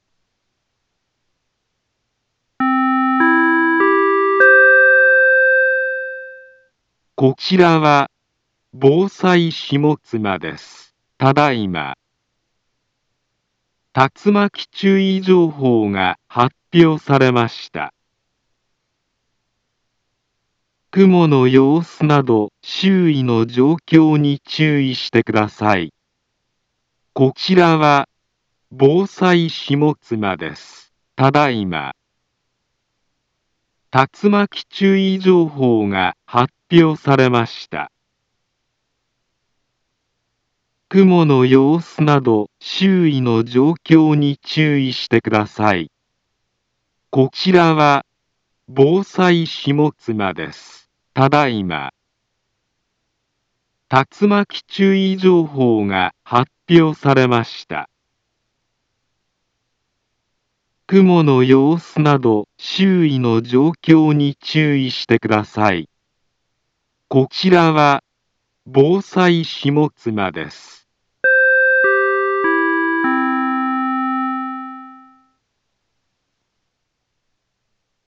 Back Home Ｊアラート情報 音声放送 再生 災害情報 カテゴリ：J-ALERT 登録日時：2024-07-25 19:19:29 インフォメーション：茨城県北部、南部は、竜巻などの激しい突風が発生しやすい気象状況になっています。